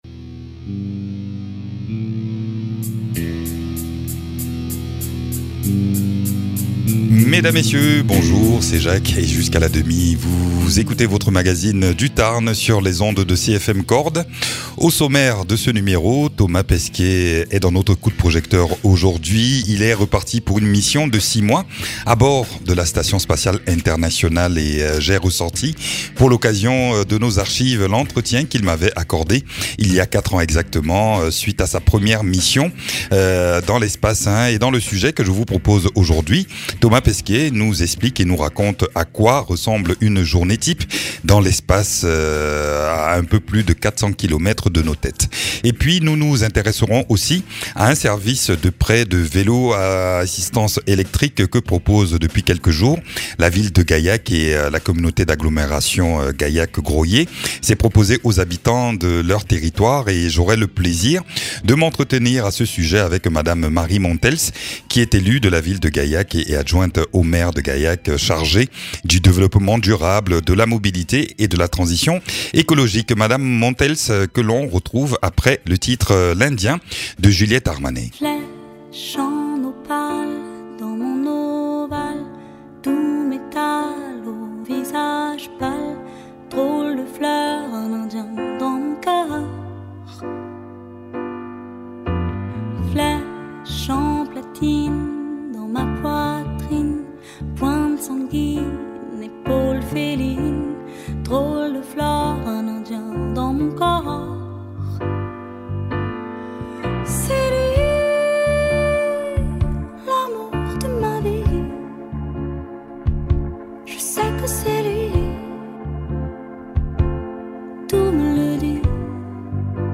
Invité(s) : Marie Montels, élue de la ville de Gaillac en charge du développement durable, de la mobilité et de la transition écologique ; Thomas Pesquet, astronaute.